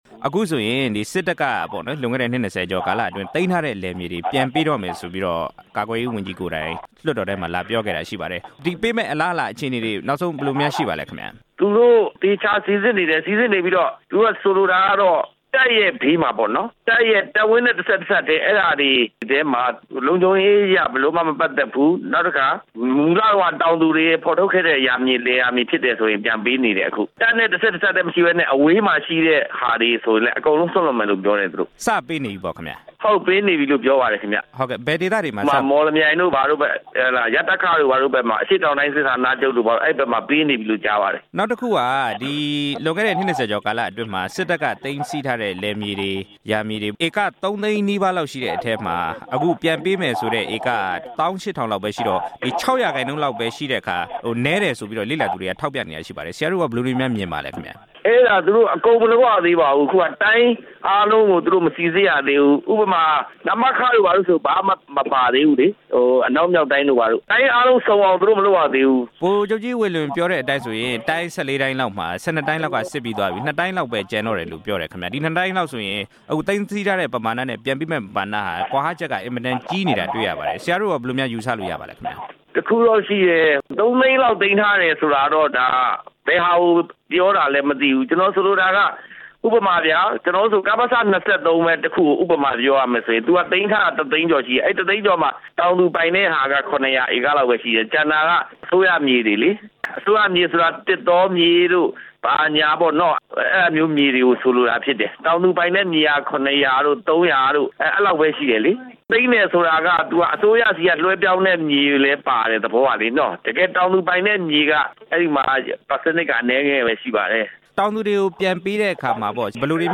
စစ်တပ်က သိမ်းယူထားတဲ့ လယ်မြေတွေ ပြန်ပေးရေးနဲ့ ပတ်သက်တဲ့ ဆက်သွယ်မေးမြန်းချက်